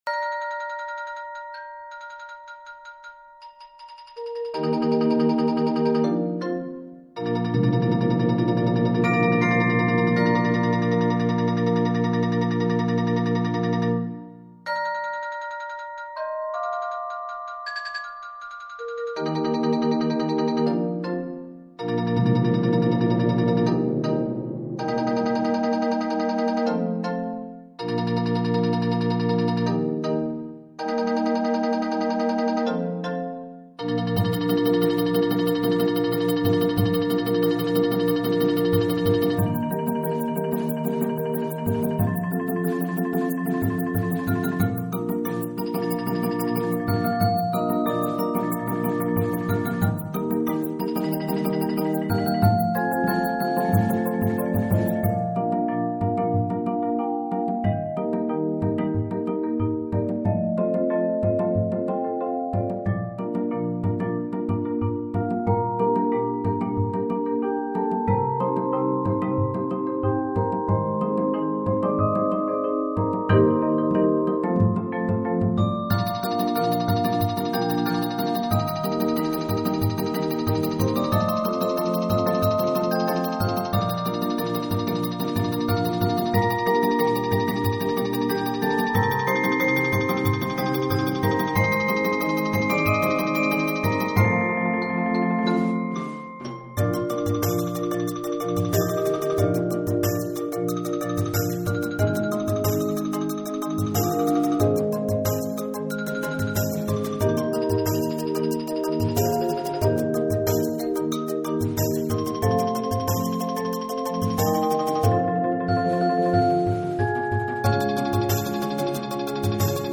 Mallet-Steelband